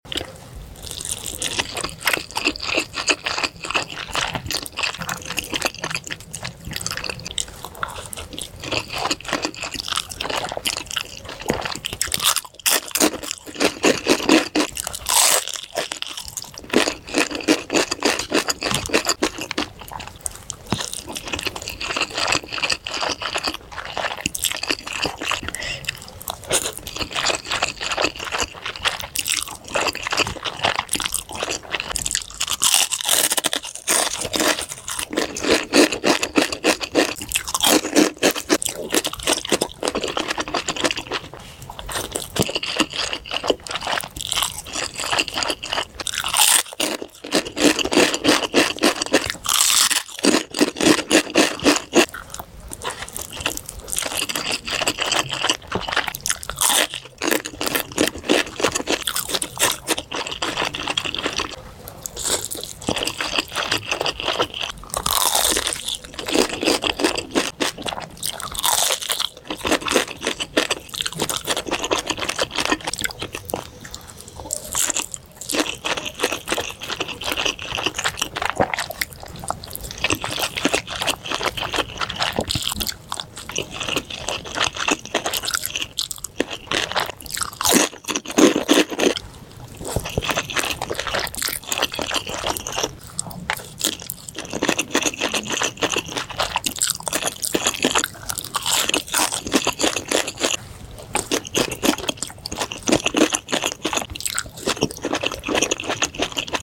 Mukbang mie gacoan